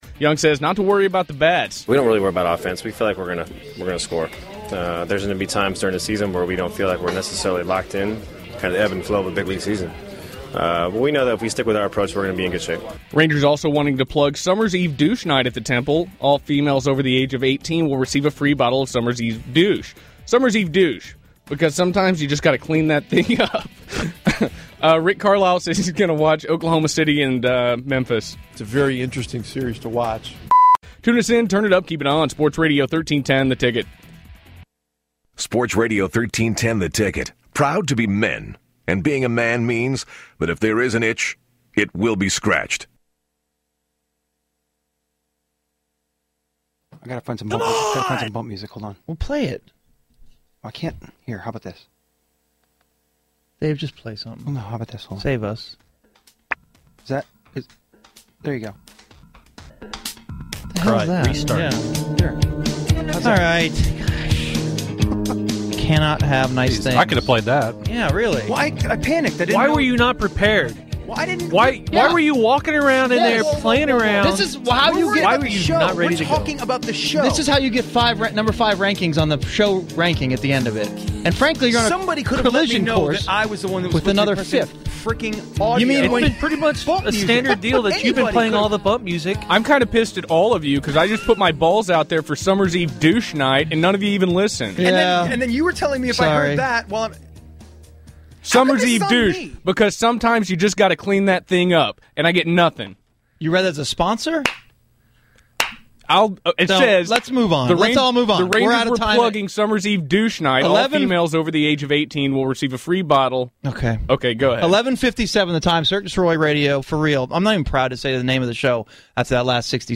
Enjoy the comedic sponsor that no one on the show heard, then the meltdown starts.